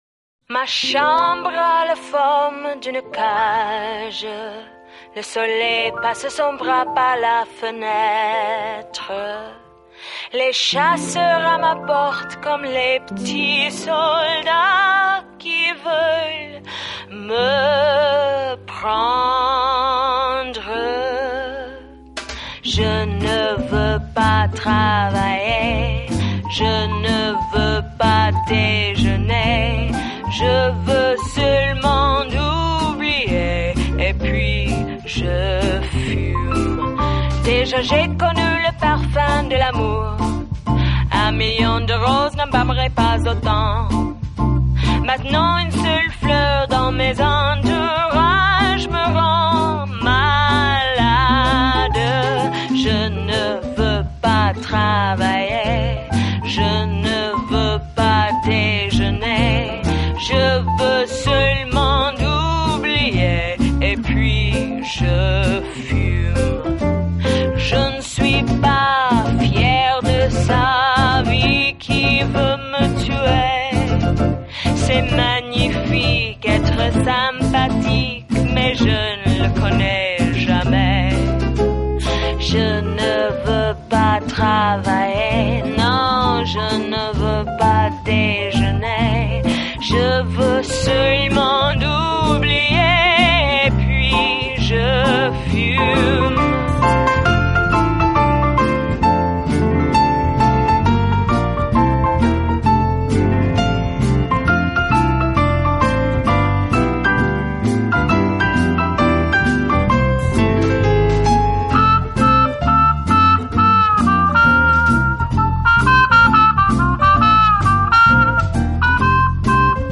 包括人声、弦乐、管乐与打击乐器群；他们展现出的乐风是多元
其音乐风格揉合爵士乐、古典乐及古巴音乐，让乐